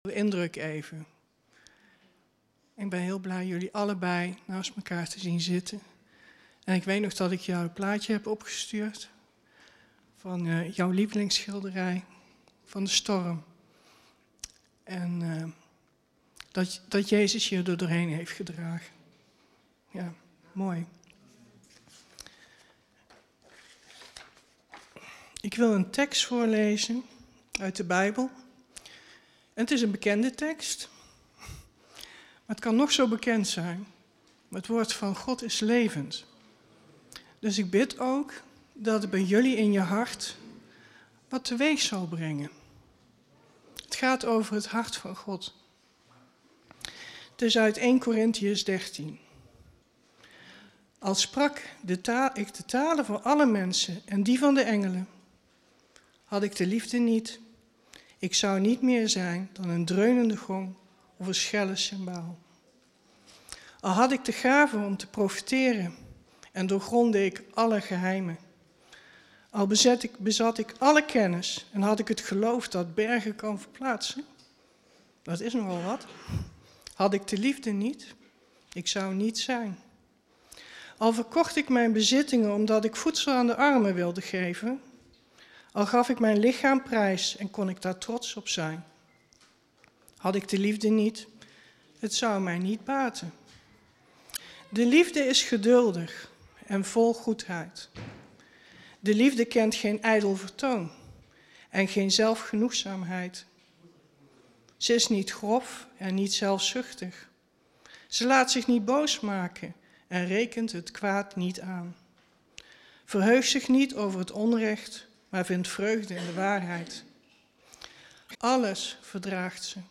(tussen 3m25 en 6m25 even geen geluid)